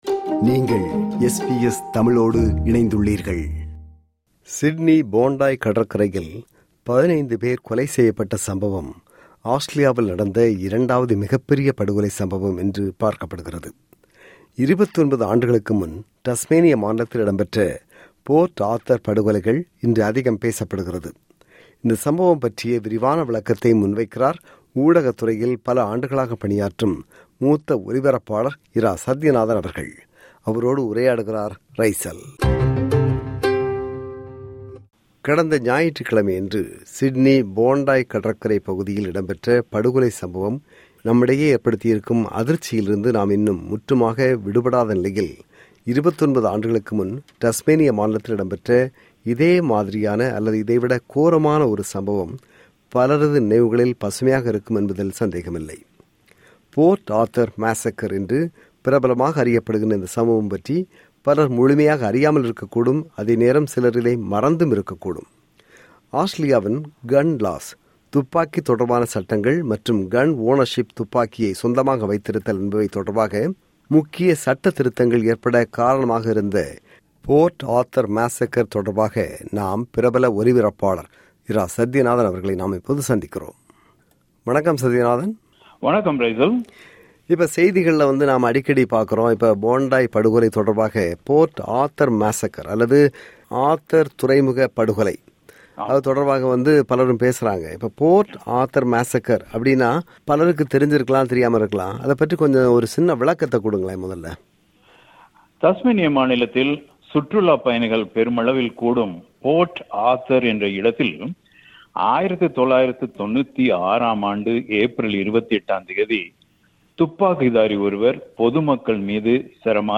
சிட்னி Bondi கடற்கரையில் 15 பேர் கொலை செய்யப்பட்ட சம்பவம் ஆஸ்திரேலியாவில் நடந்த இரண்டாவது மிகப் பெரிய படுகொலை சம்பவம் எனப்படுகிறது. 29 ஆண்டுகளுக்கு முன் தஸ்மேனிய மாநிலத்தில் இடம்பெற்ற Port Arthur படுகொலைகள் இன்று அதிகம் பேசப்படுகிறது. இந்த சம்பவம் பற்றிய விரிவான விளக்கத்தை முன்வைக்கிறார் ஊடகத்துறையில் பல ஆண்டுகளாக பணியாற்றும் மூத்த ஒலிபரப்பாளர்